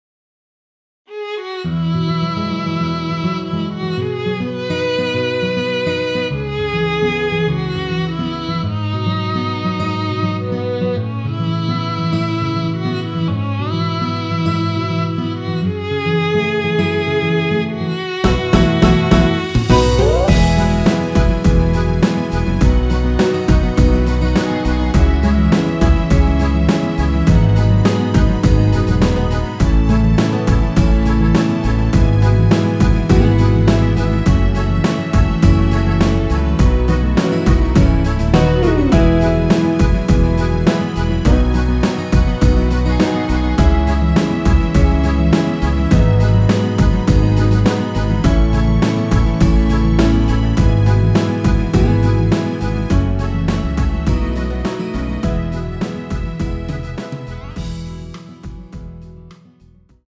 원키에서(-2)내린 MR입니다.
앞부분30초, 뒷부분30초씩 편집해서 올려 드리고 있습니다.
중간에 음이 끈어지고 다시 나오는 이유는
곡명 옆 (-1)은 반음 내림, (+1)은 반음 올림 입니다.